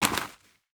Light  Dirt footsteps 1.wav